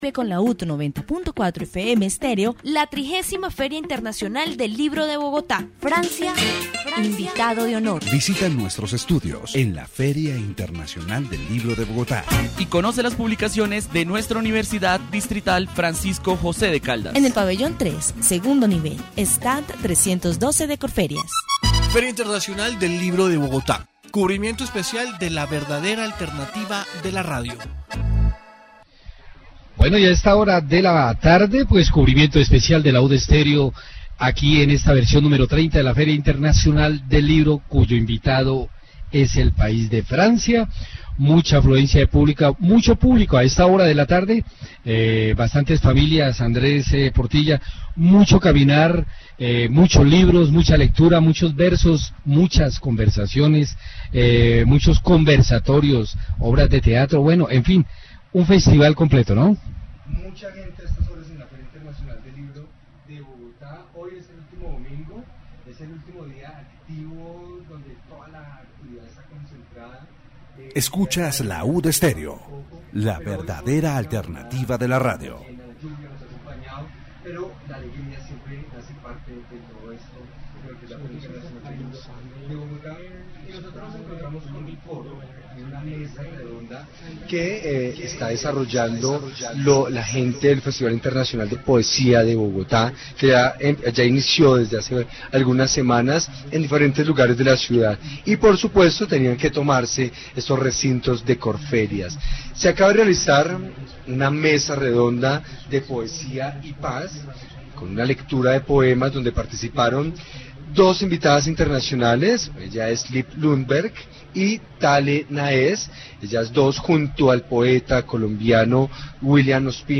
Special report from the 30th Bogotá International Book Fair, with guest country France.